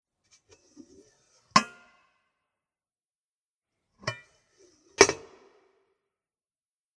Open and close the ball
29109_Kugel_aufklappen_und_zuklappen.mp3